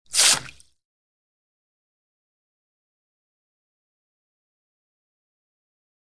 Water: Short Spray.